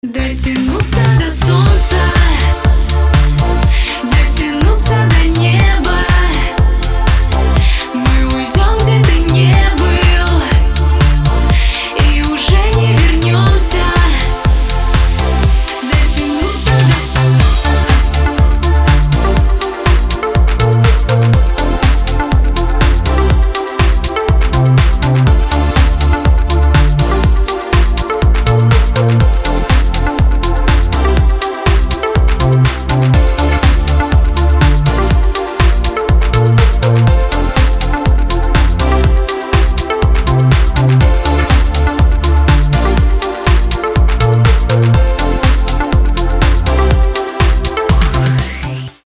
• Качество: 320, Stereo
женский вокал
dance
Electronic
Club House
электронная музыка
спокойные
кавер